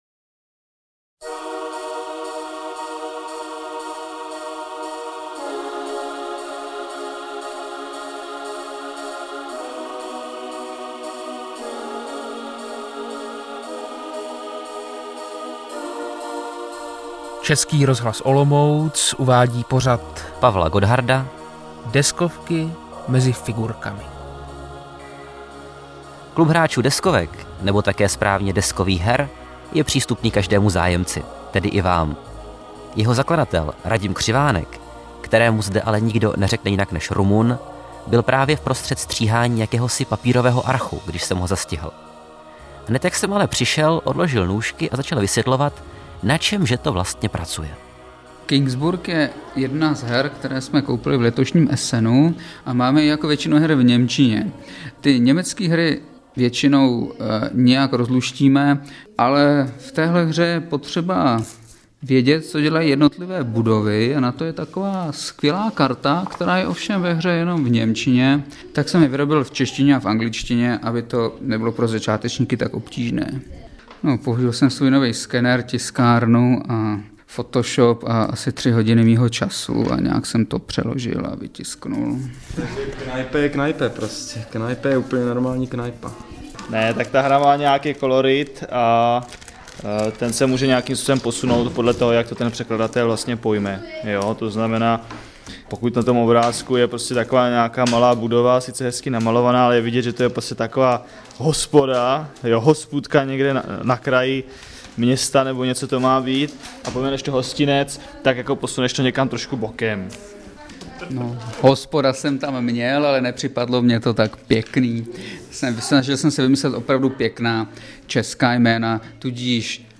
Záznam rozhlasové reportáže